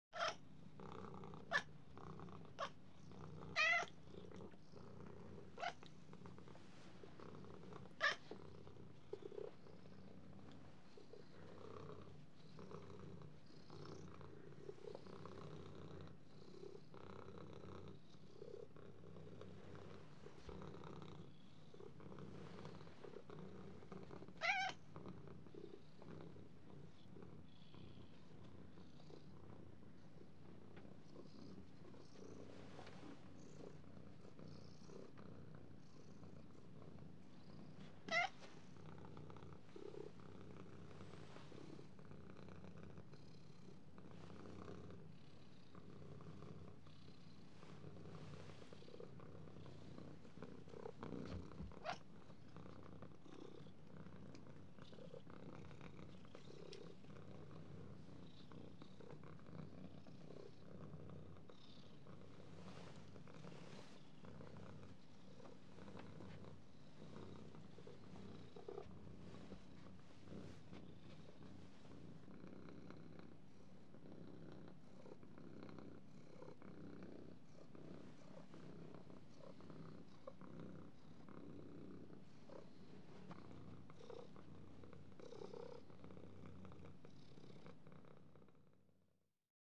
Звуки кошек
Мурлыканье домашней кошки